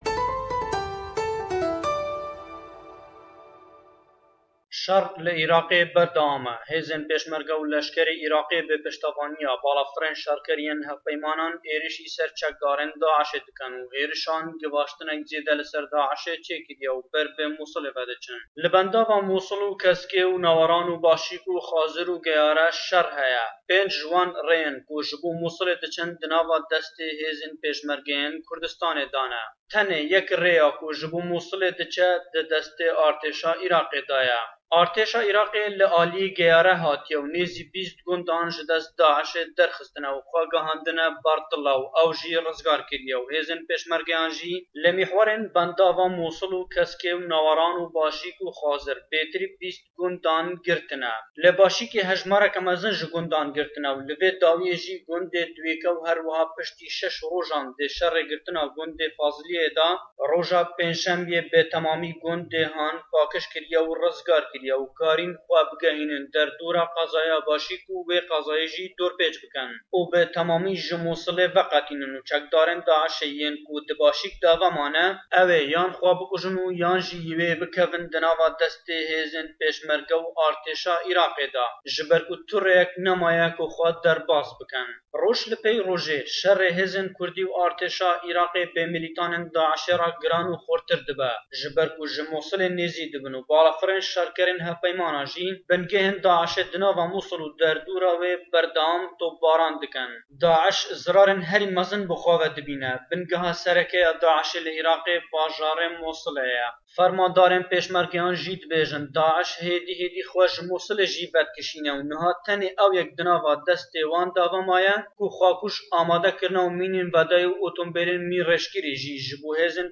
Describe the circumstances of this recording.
le berekanî cengî Pêshmeregewe em raportey boman amade kirdûwe. Hêzî Pêshmergey Kurdistan û hêze Êraqêkan nizîk debine le binkey serekî Da'iş ke Êraq ke şarî Mûsille.